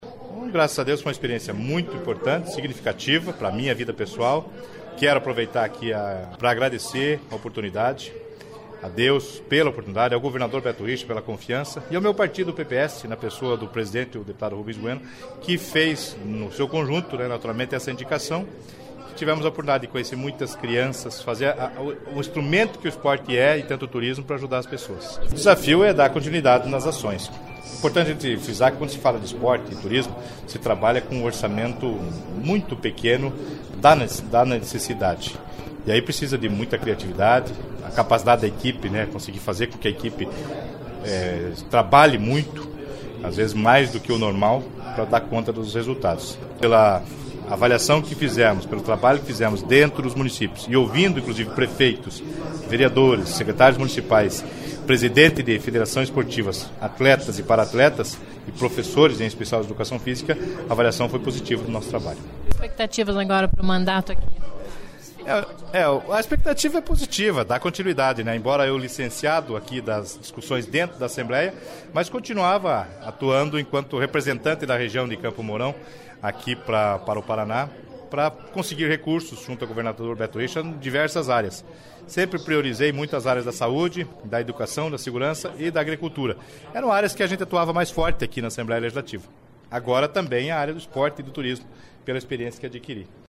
Ouça entrevista na íntegra do parlamentar do PPS, que fala um pouco do trabalho que fez à frente da Secretaria estadual de Esporte e Turismo e das expectativas para o mandato que reassume.